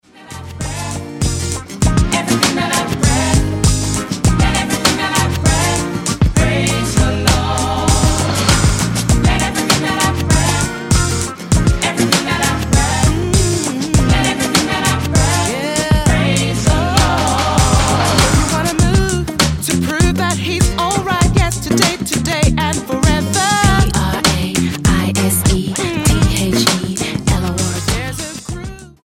R&B Album
Style: Gospel Approach: Praise & Worship